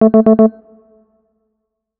Ringtone.aif